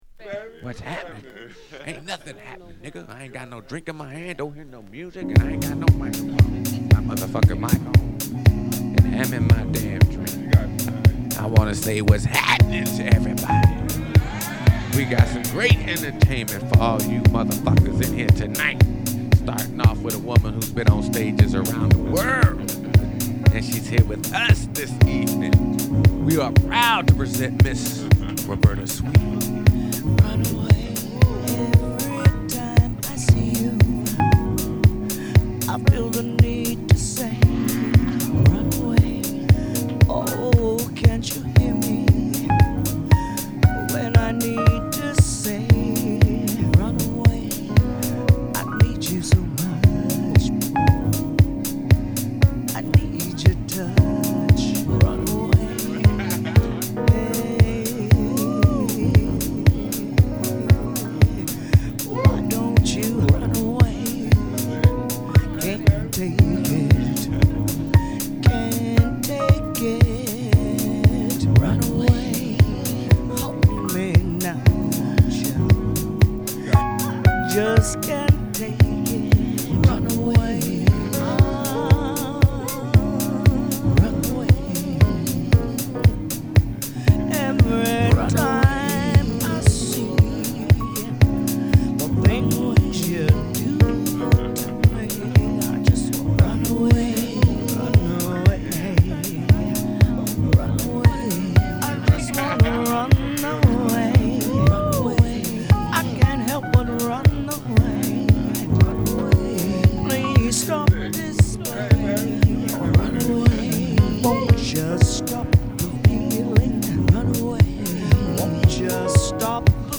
デトロイトハウス名曲！